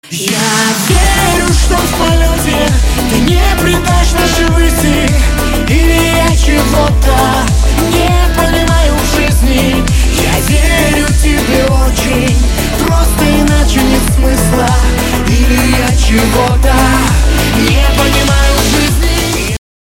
поп
dance
мужской и женский вокал